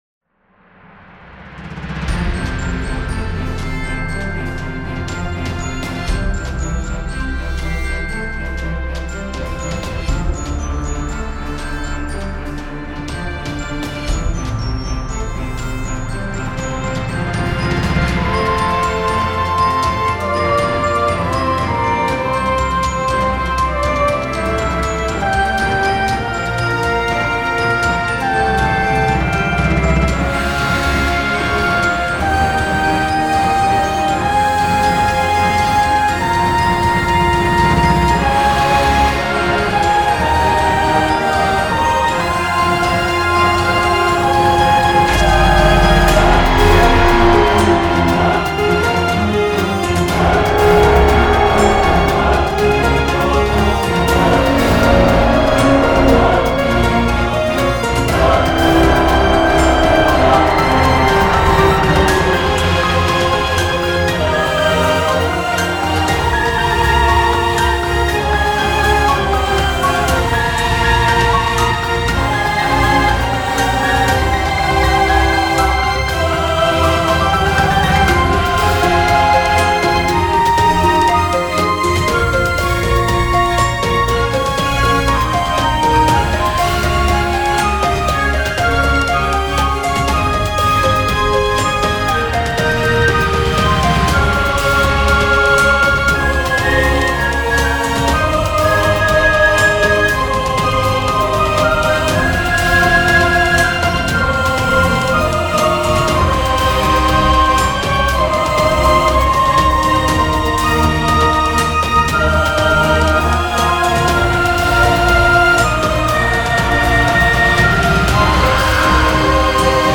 quand au troisième "Décorporation" belle musique mais à mon avis trop épique et par cela ne répondant pas au thème.
Je dirais que je n'ai pas eu le temps de l'achever complètement, notamment niveau rythmique et aussi peut être un meilleur mixage et travail des mes instruments.